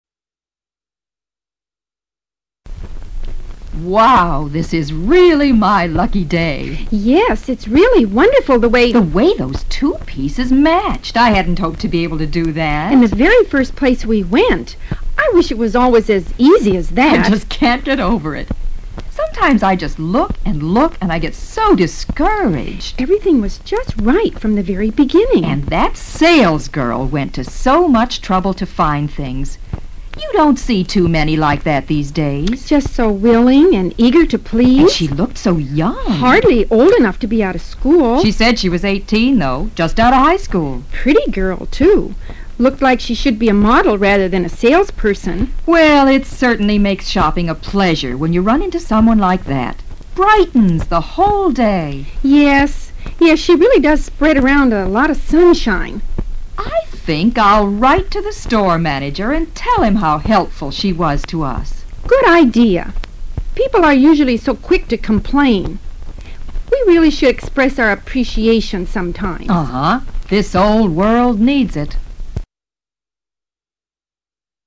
Two Shoppers
In this first step, you will listen through this conversation between two shoppers onboard the bus.
-   What are these two women like?